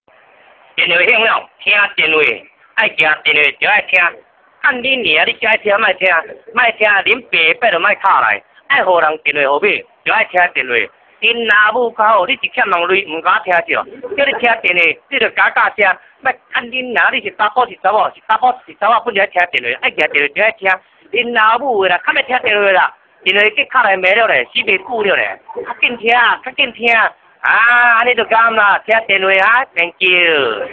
Call answering response
answercall.mp3